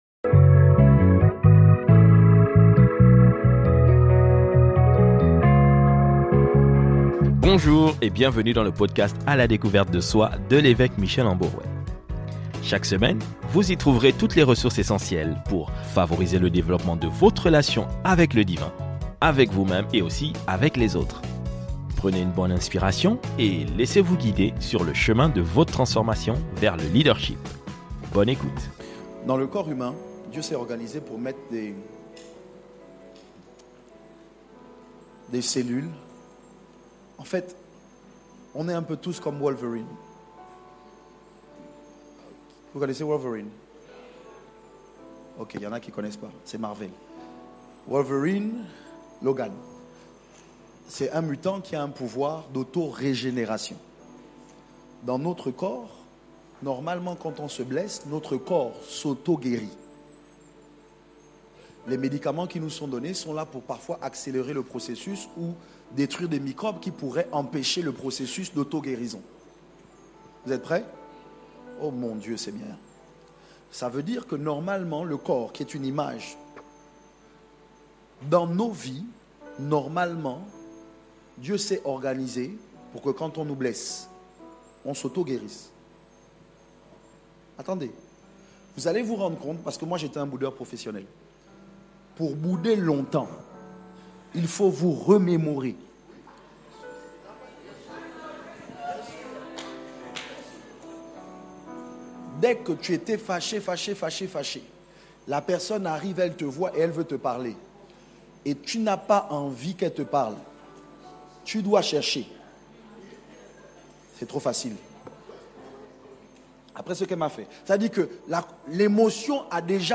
Des conférences qui donnent l'occasion à l'évêque Michel et ses invités, d'échanger avec les jeunes directement sur des sujets bien précis.